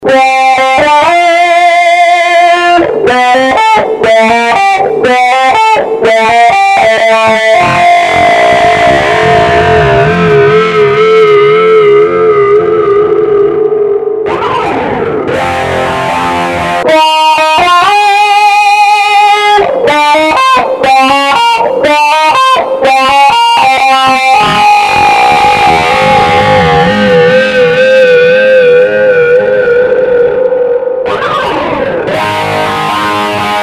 Вложения 02_Guitar_Solo_1__3_.mp3 02_Guitar_Solo_1__3_.mp3 525,7 KB · Просмотры: 294